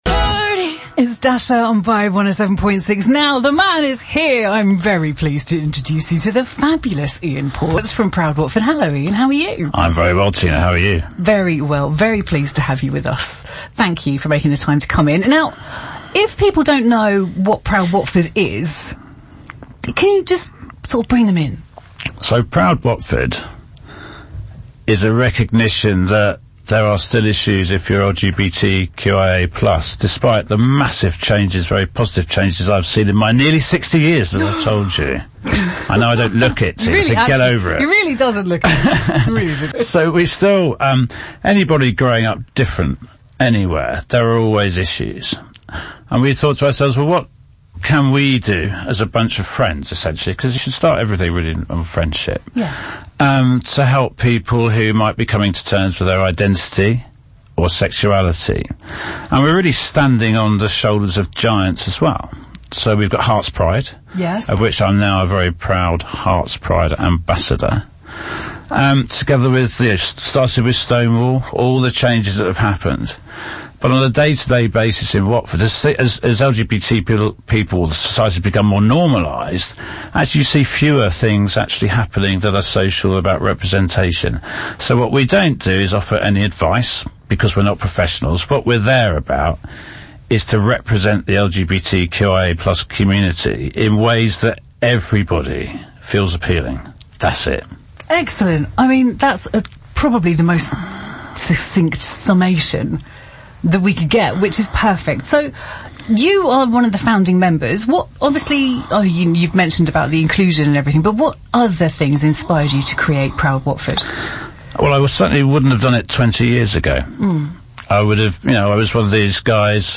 Their mission is to encourage ongoing efforts towards a society that recognises and values individuality. The Impact of Proud Watford During the interview